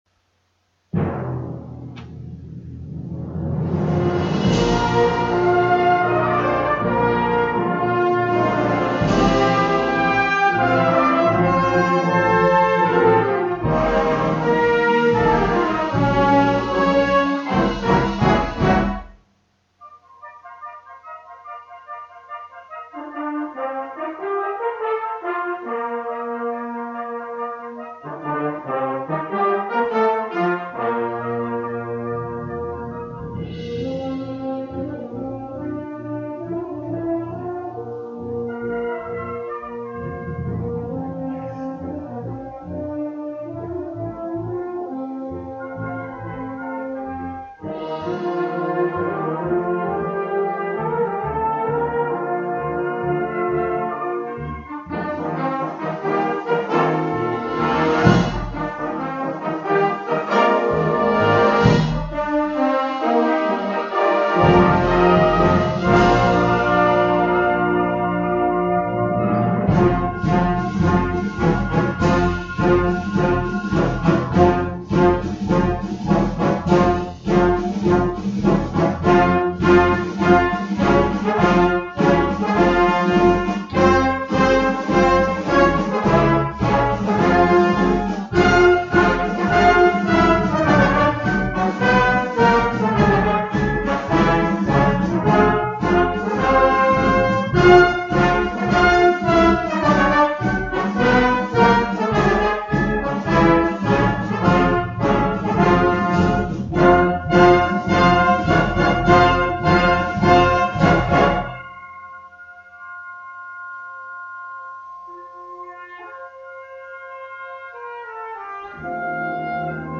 Virtuelles Konzert: Herzlich Willkommen zum Konzertabend!